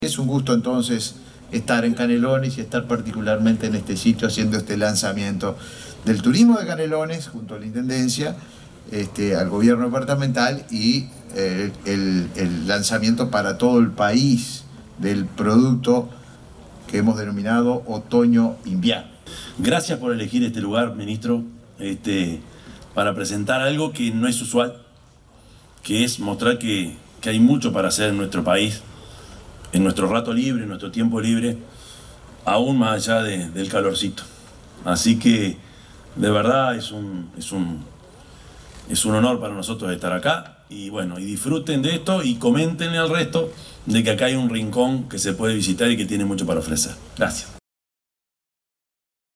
El Ministerio de Turismo, junto al Gobierno de Canelones y al Municipio de Santa Lucía, llevó adelante el primer lanzamiento nacional de la temporada otoño-invierno en la Quinta Capurro de Santa Lucía.
Oratoria del Intendente de Canelones, Yamandú Orsi, en el lanzamiento nacional de la temporada otoño-invierno.